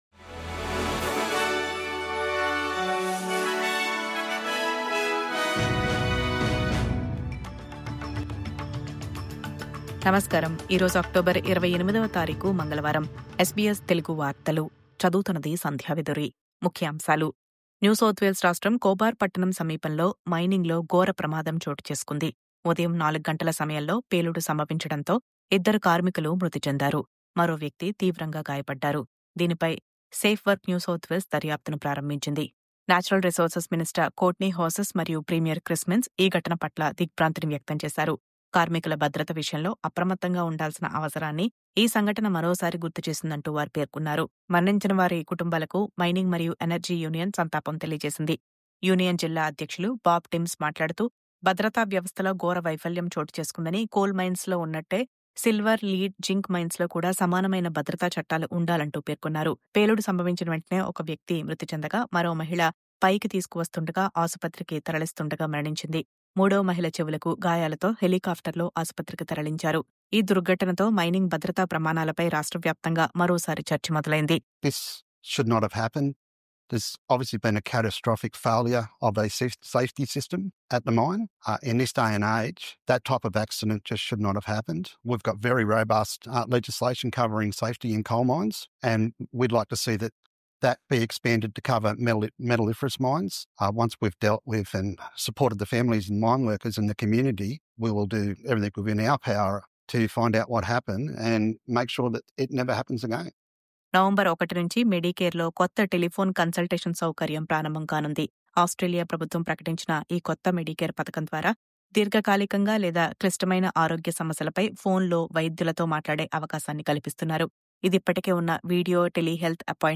News update: NSW కోబార్‌లో మైనింగ్ పేలుడు కారణంగా ఇద్దరు మృతి..